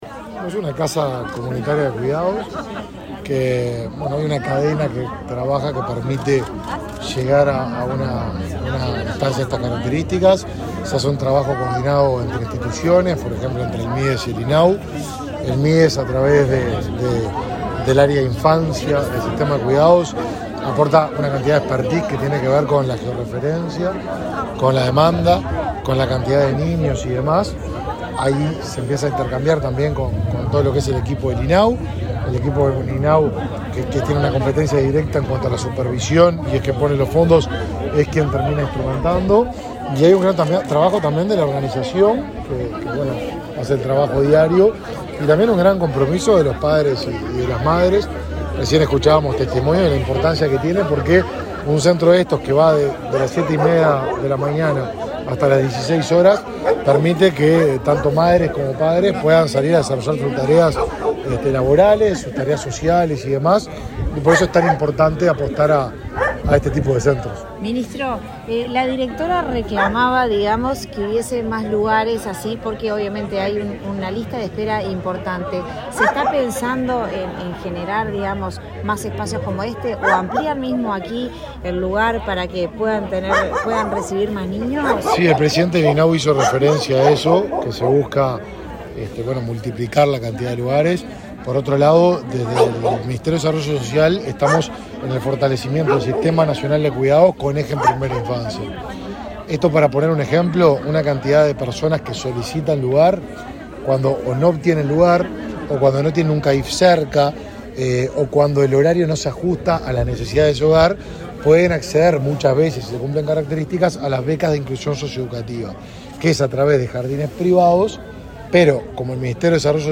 Declaraciones a la prensa del ministro de Desarrollo Social, Martín Lema
Declaraciones a la prensa del ministro de Desarrollo Social, Martín Lema 22/04/2022 Compartir Facebook X Copiar enlace WhatsApp LinkedIn El ministro de Desarrollo Social, Martín Lema, inauguró este viernes 22, junto con el presidente del Instituto del Niño y Adolescente de Uruguay (INAU), Pablo Abdala, una casa comunitaria de cuidados en Paso Carrasco, Canelones. Luego, dialogó con la prensa.